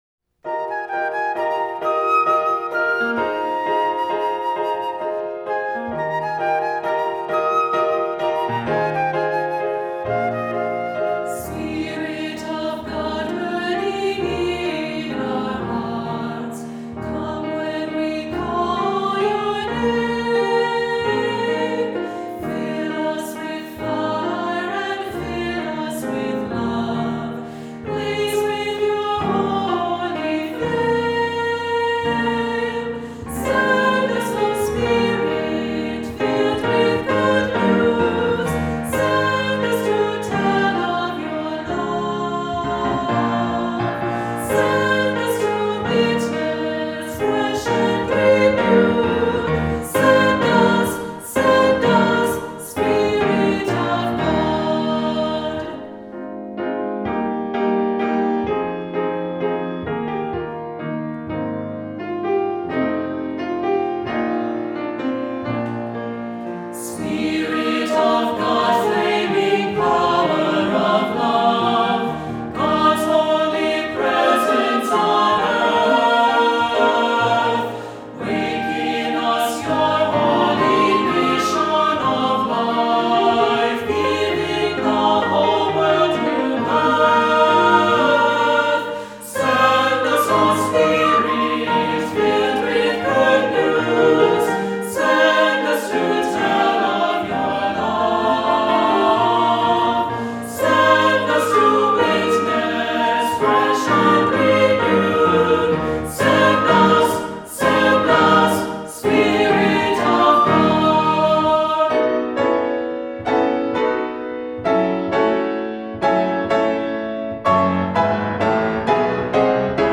Voicing: 2-part Choir,Assembly